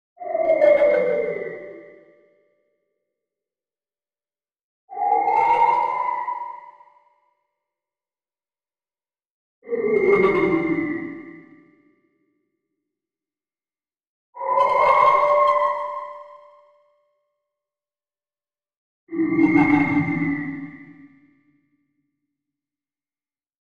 На этой странице собраны звуки души — необычные аудиокомпозиции, отражающие тонкие эмоциональные состояния.
Звук потерянной души в потустороннем мире